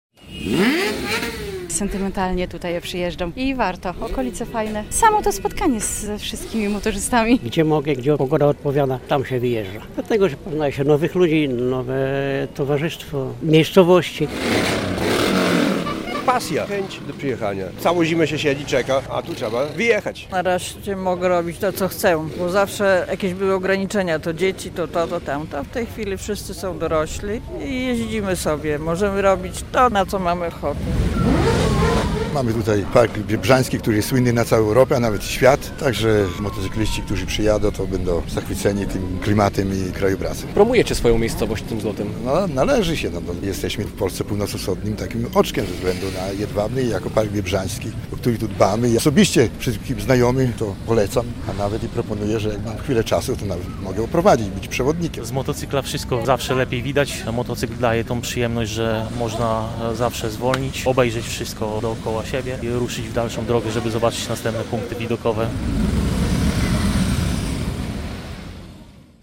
Zlot motocyklowy w Jedwabnem, 6.07.2024 r.